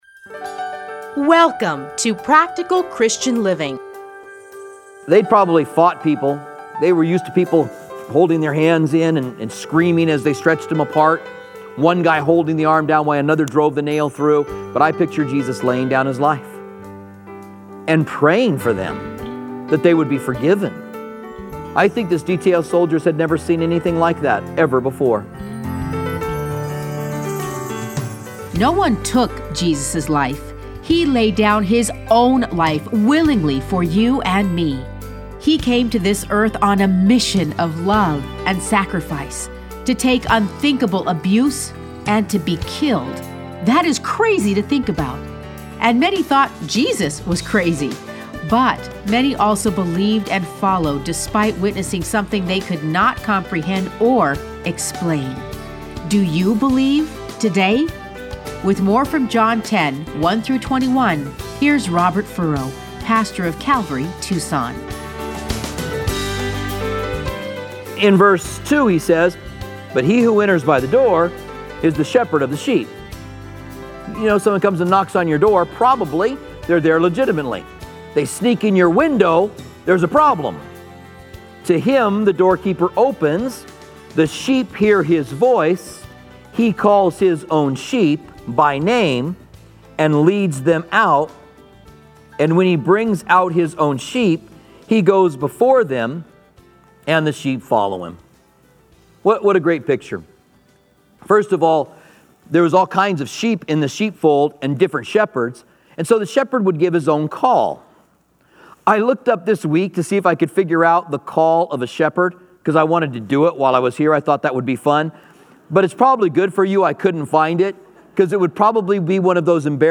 Listen to a teaching from John 10:1-21.